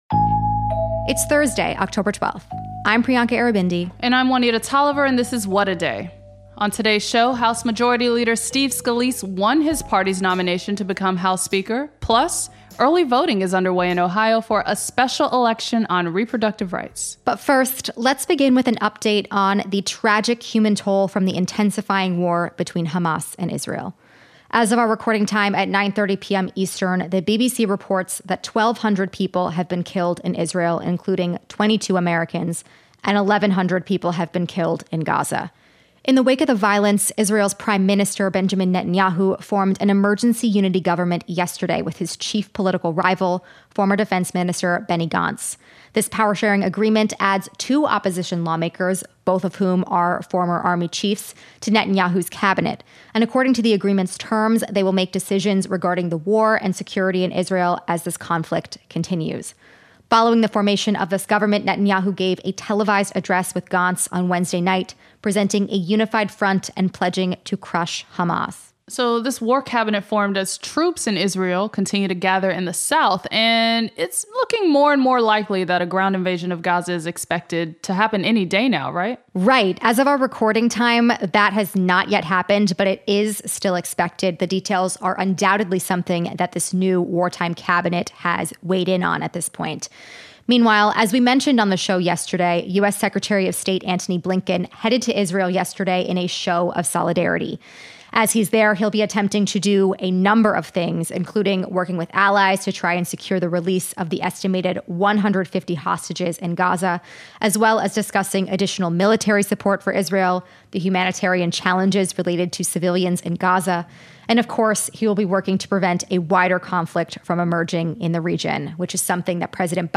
a PhD student living in Tel Aviv